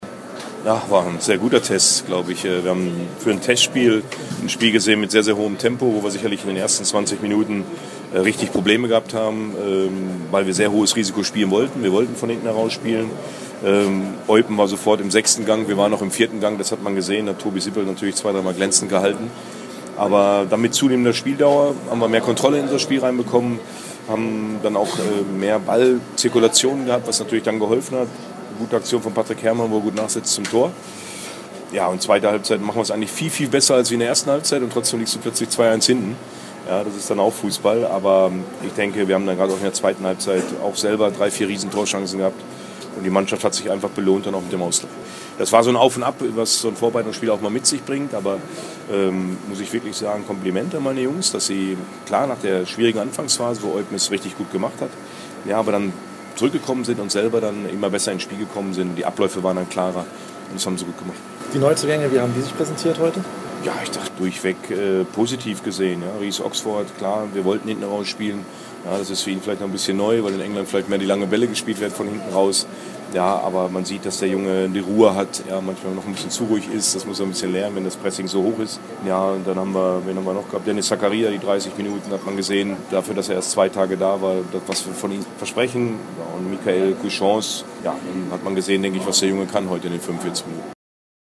im Gespräch mit Borussen-Coach Hecking